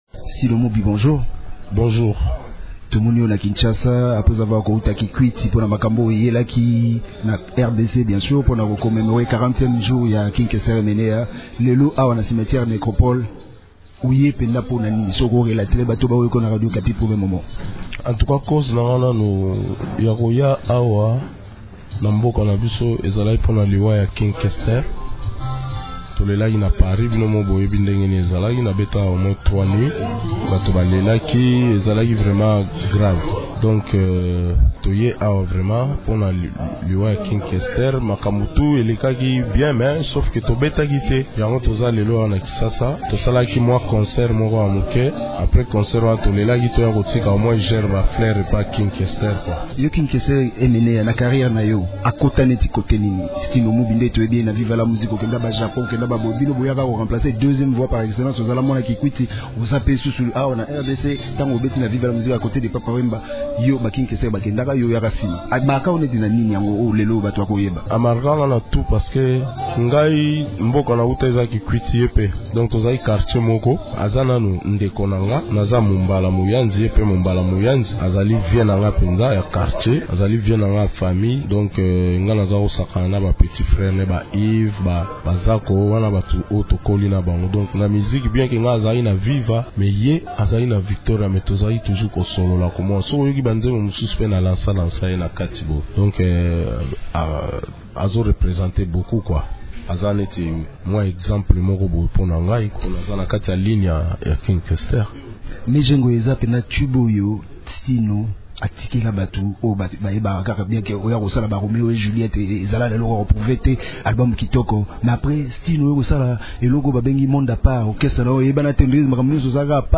Dans une interview accordée à Radio Okapi mercredi 14 mai, il a également annoncé la sortie prochaine d’un album de 10 titres.